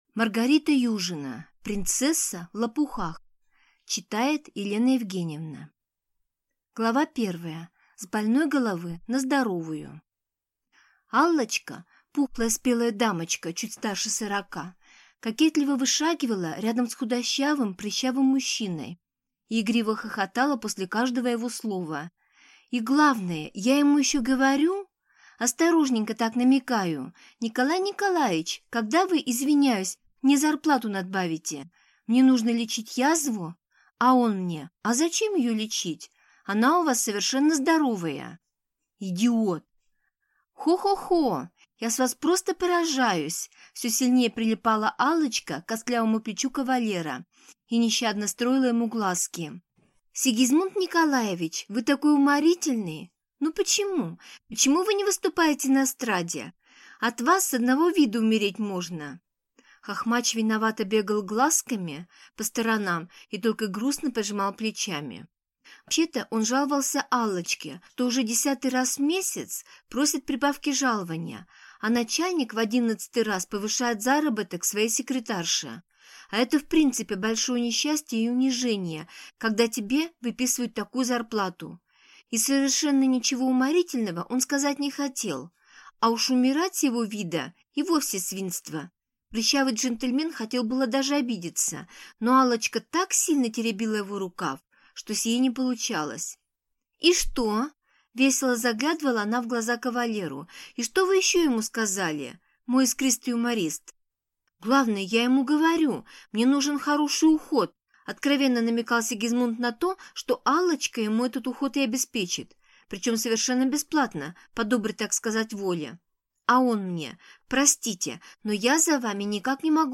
Аудиокнига Принцесса в лопухах | Библиотека аудиокниг